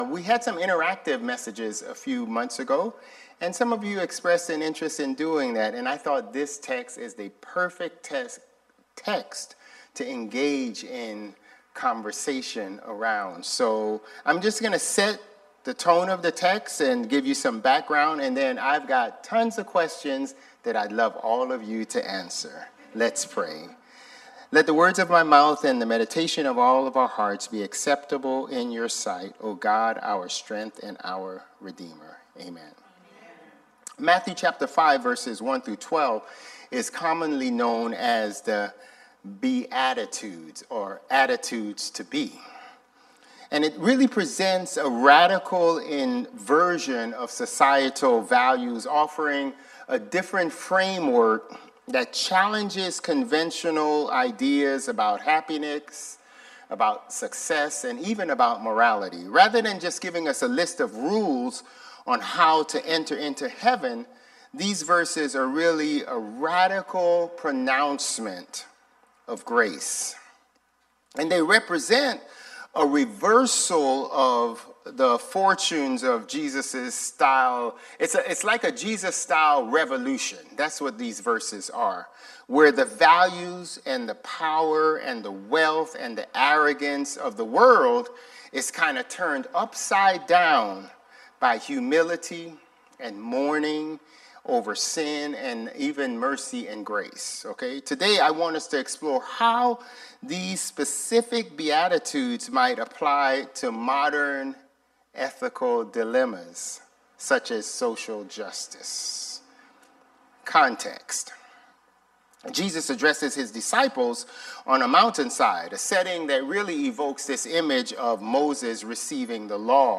This interactive sermon explores Matthew 5:1-12, commonly known as the Beatitudes, presenting them as a radical inversion of societal values rather than mere rules for entering heaven. The message challenges conventional ideas about happiness, success, and morality by examining how Jesus redefines power through humility, mourning, meekness, and mercy. Through congregational dialogue, the sermon addresses contemporary ethical dilemmas including social justice, the nature of blessedness versus happiness, and the tension between passive acceptance and active peacemaking.